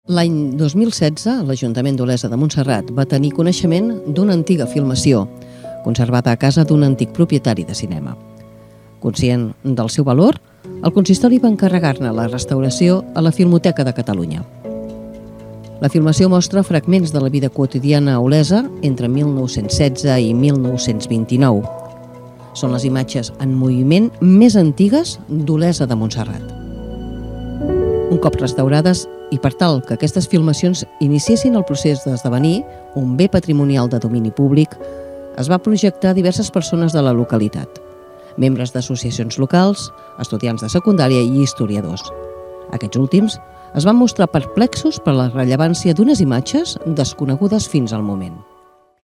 Veu en off documental Olesa 1916